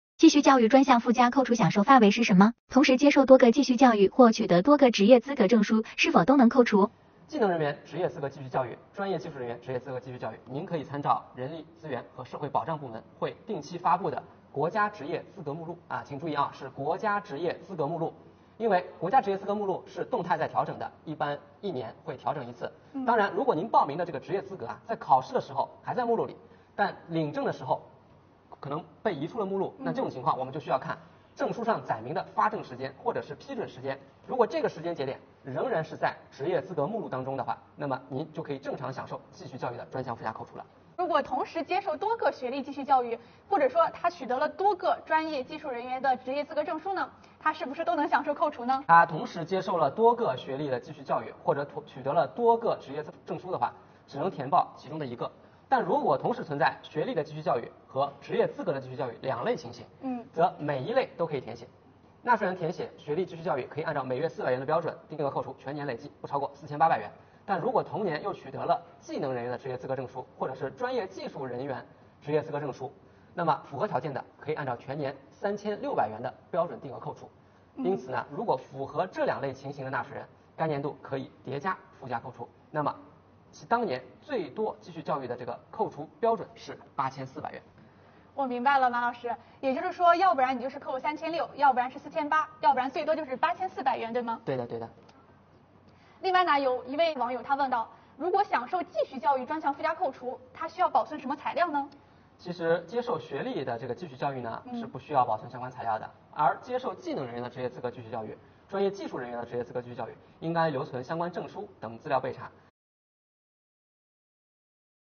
今天主播就来和您聊聊关于继续教育专项附加扣除的那些事，戳视频来学习~